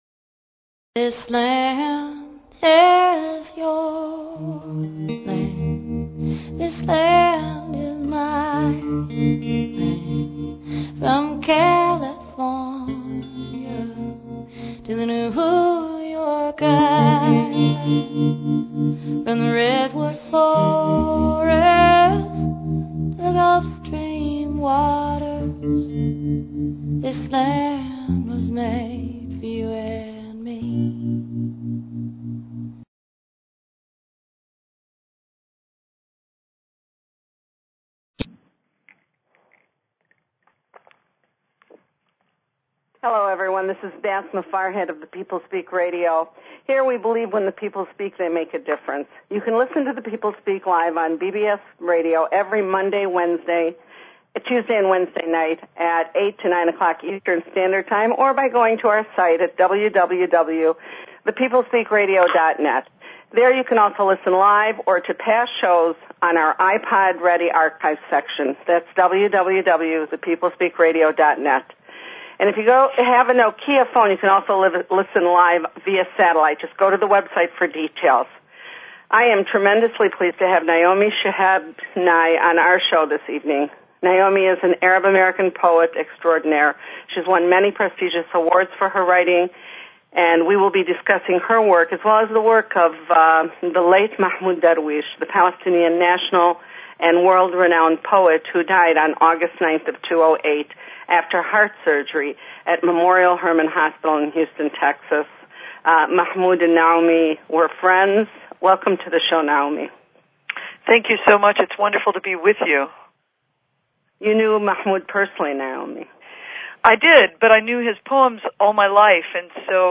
Talk Show Episode, Audio Podcast, The_People_Speak and Naomi Shihab Nye, Poet on , show guests , about , categorized as
The show features a guest interview from any number of realms of interest (entertainment, science, philosophy, healing, spirituality, activism, politics, literature, etc.).